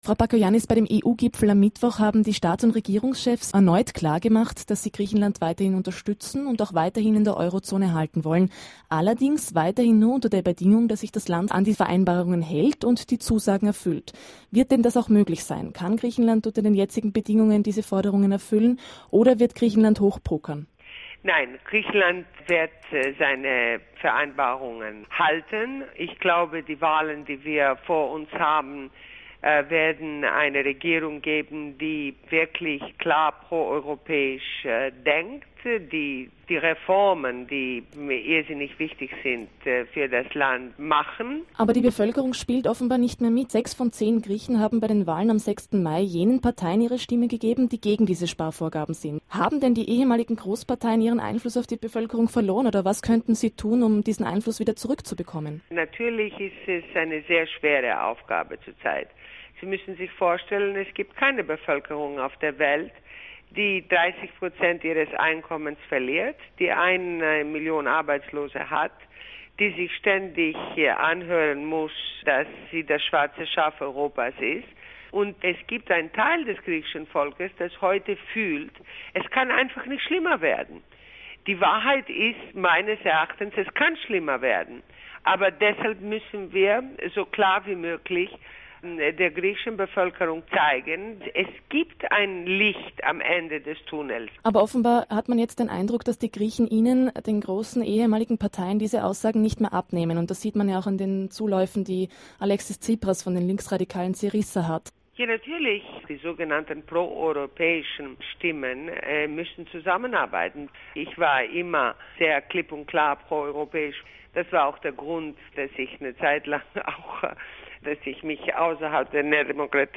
Συνέντευξη στο αυστριακό ραδιόφωνο ORF